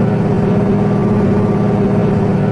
engine loop.wav